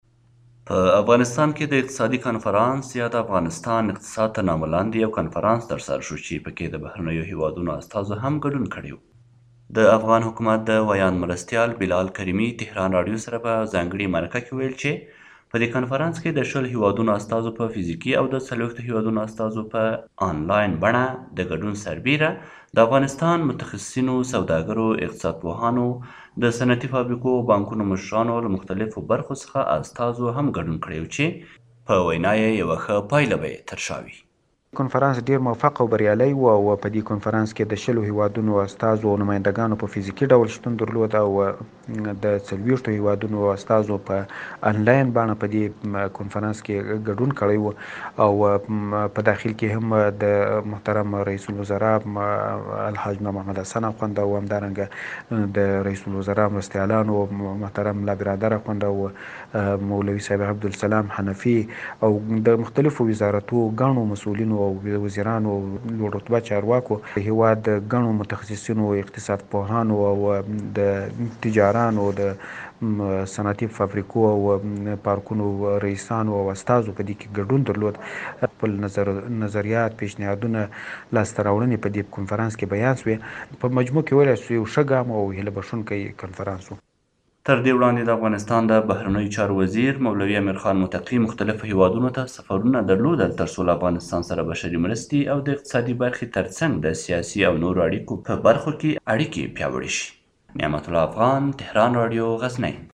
افغانستان کښې اقتصادي کانفرنس جوړ شوی نور تفصیل په راپور کښې لرو
د افغانستان د حکومت د ویاند مرستیال بلال کریمي له تهران راډیو سره په ځانګړې مرکه کې وویل چې په دې کنفرانس کې د (۲۰) هیوادونو استازو په فزیکي او د(۴۰) هیوادونو استازو په انلاین بڼه دګډون سربېره دافغانستان ګڼو متخصیصینو ، سوداګرو، اقتصاد پوهانو، دصنعتي فابریکو او بانکونو مشرانو او له مختلفو برخو څخه استازو هم ګډون کړی وو چې په وینا یې یوه ښه پایله به یې ترشا وي.